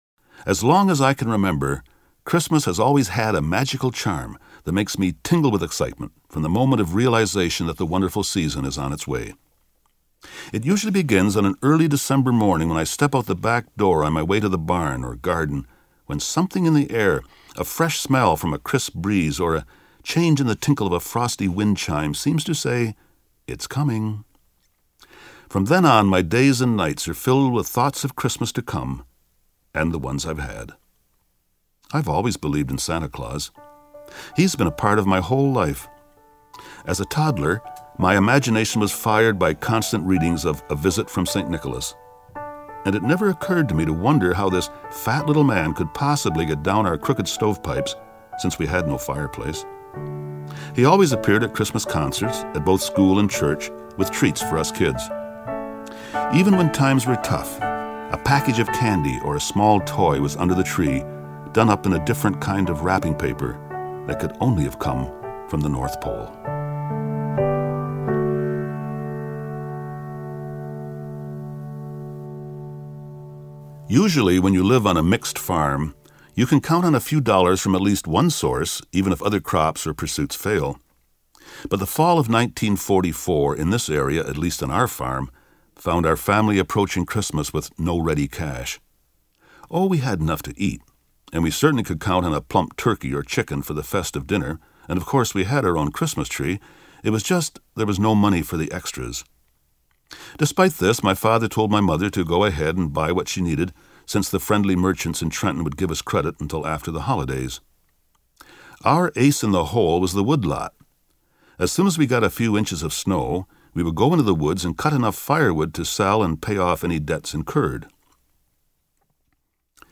Click the arrow to listen to The Christmas Story, read by Roy.
Roys-Christmas-Story.mp3